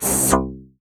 TSK  AREM.wav